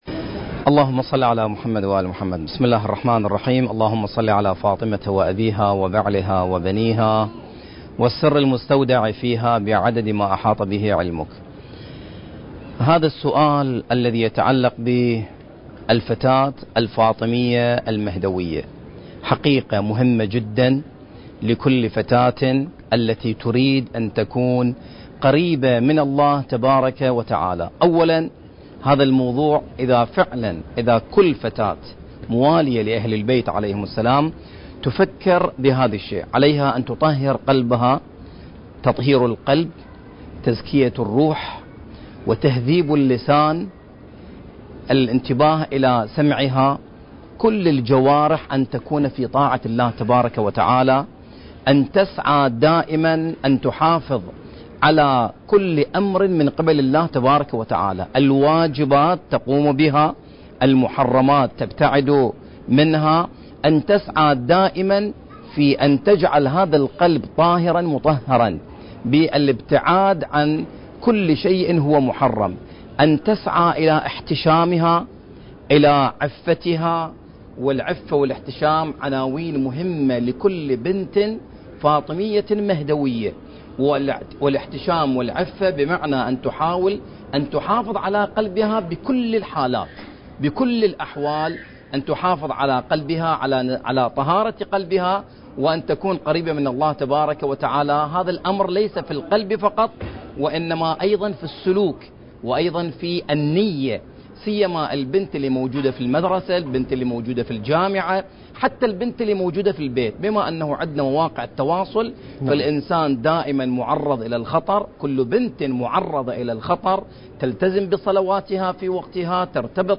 برنامج: يا مهدي الأمم المكان: مسجد السهلة المعظم